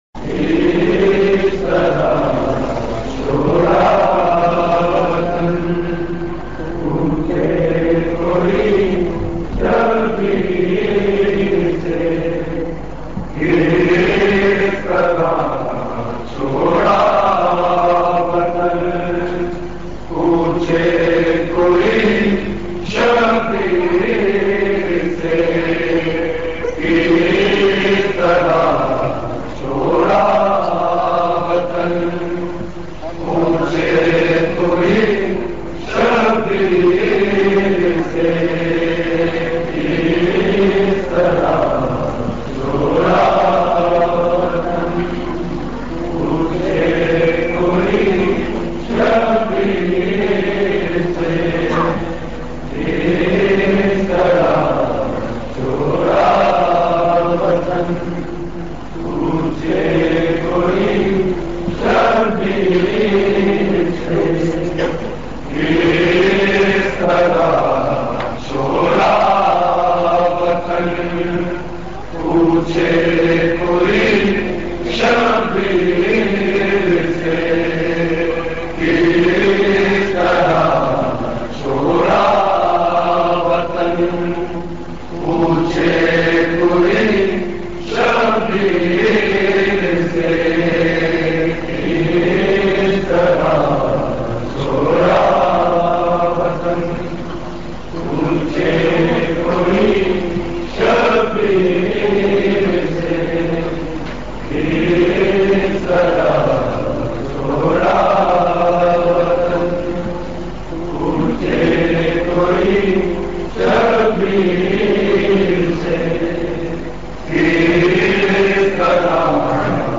Recording Type: Live